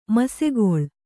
♪ masegoḷ